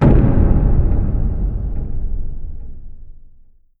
Scared.wav